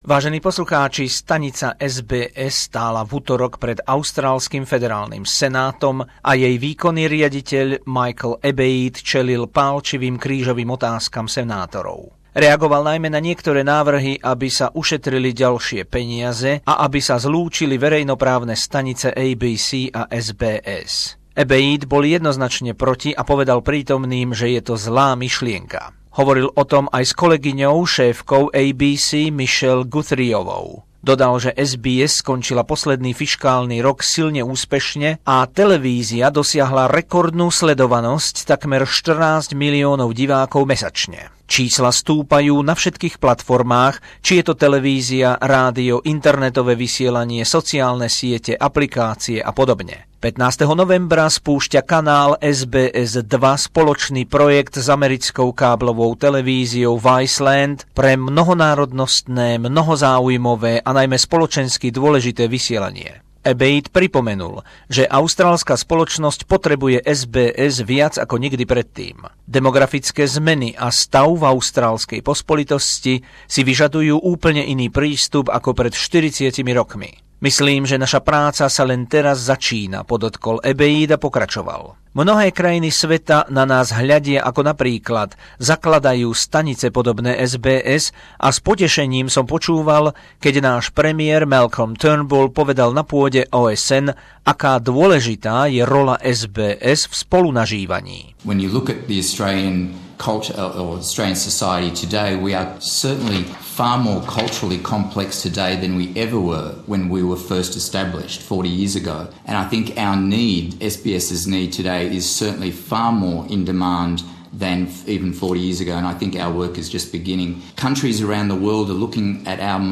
Príspevok zo spravodajskej dielne SBS.